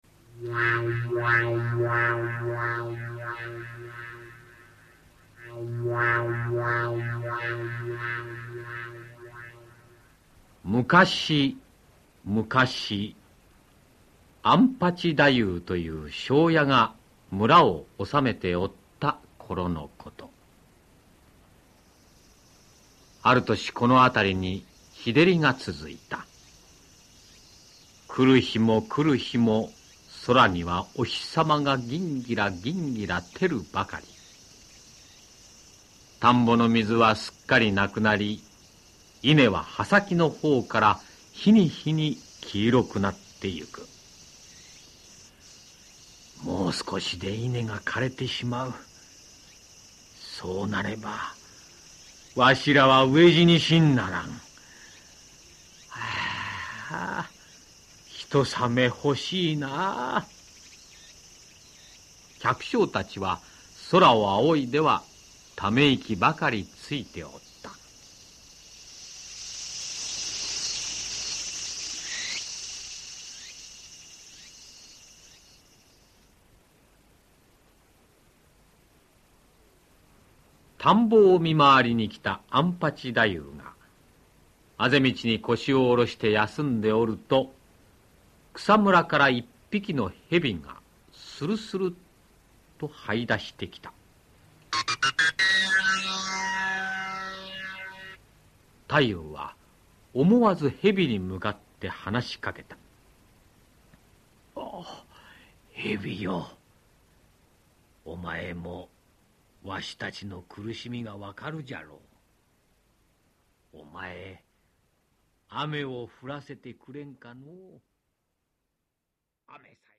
[オーディオブック] 夜叉ヶ池